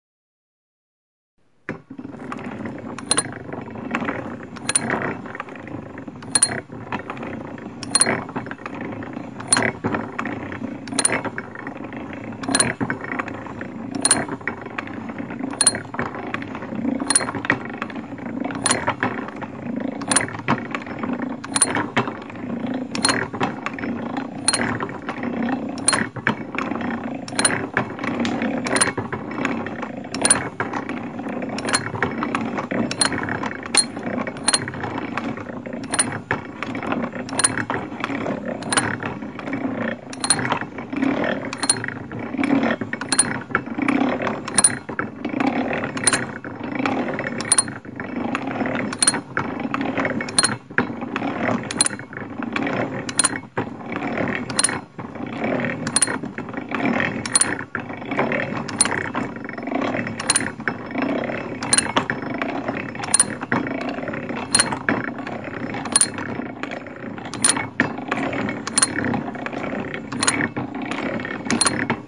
Download Gear sound effect for free.